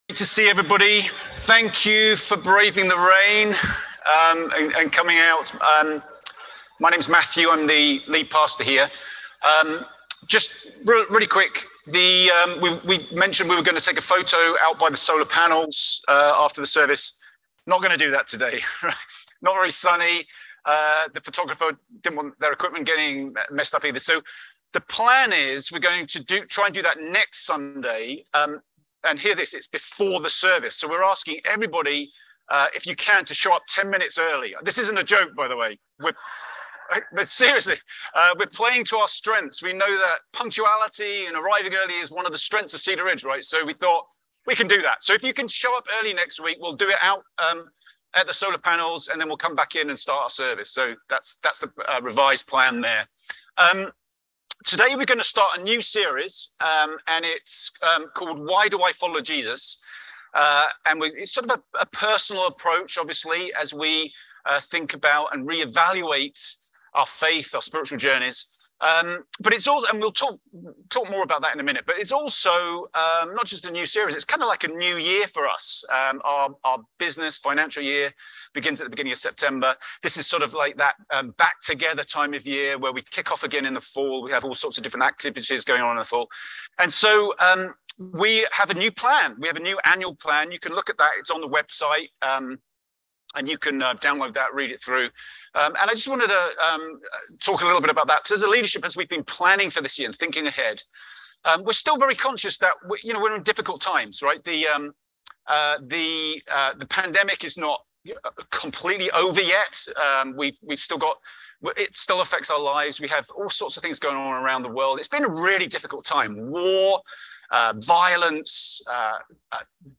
A message from the series "Why I Follow Jesus."